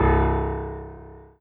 piano-ff-04.wav